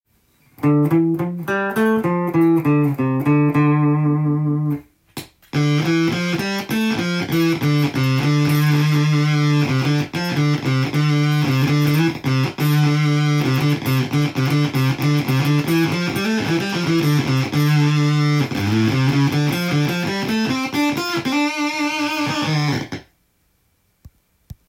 ルックスが奇抜で音も衝撃でした。
部類はファズになるようですが、ファズというよりシンセに近いギターエフェクターのようです。
試しに弾いてみました